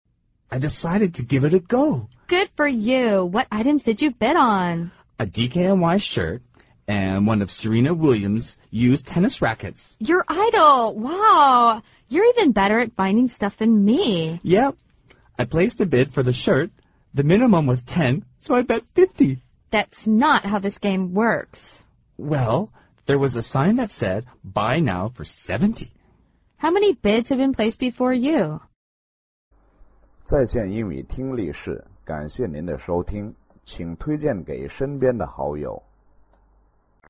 美语会话实录第137期(MP3+文本):Give it a go